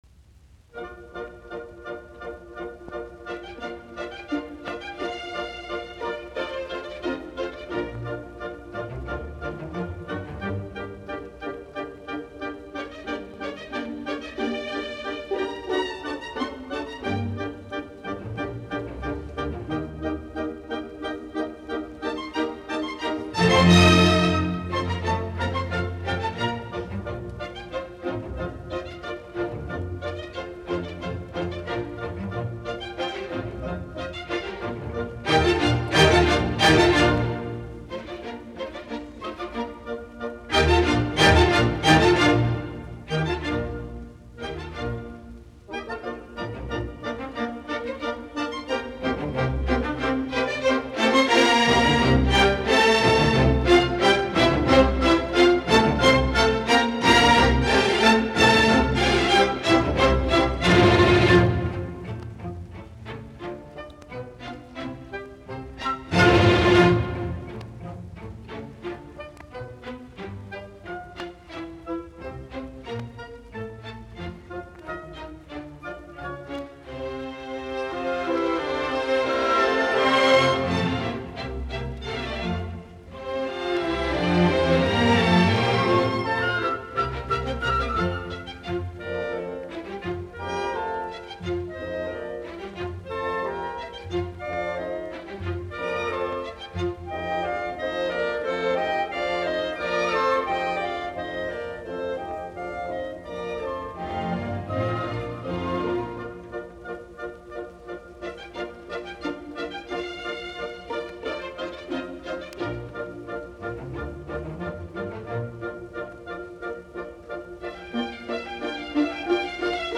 Allegro vivace e con brio